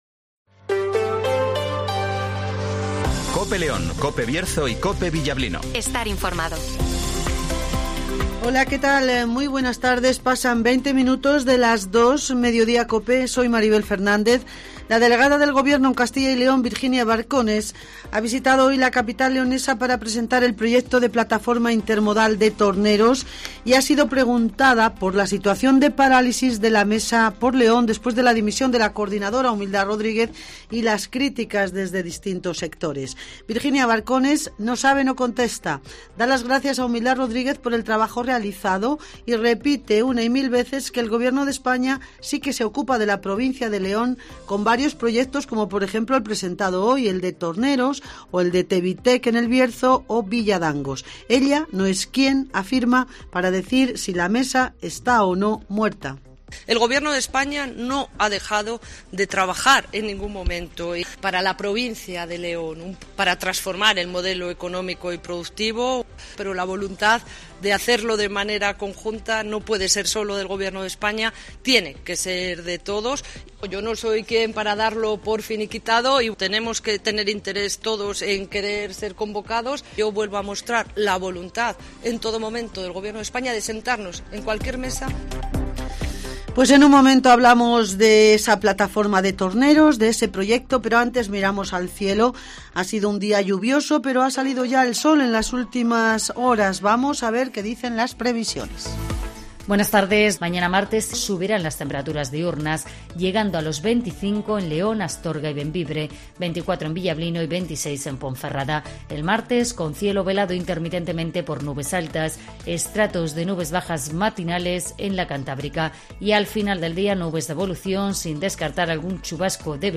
Informativo Mediodía en Cope León